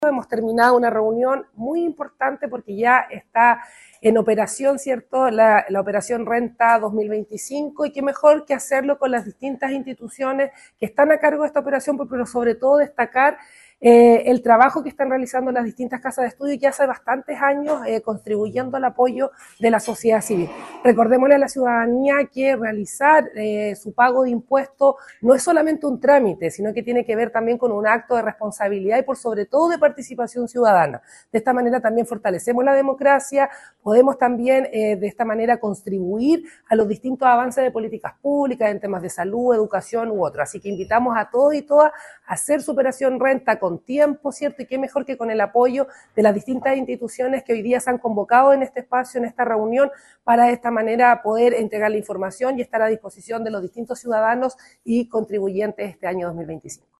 La seremi de Gobierno, Jacqueline Cárdenas, destacó la importancia de la responsabilidad tributaria como un acto de compromiso ciudadano.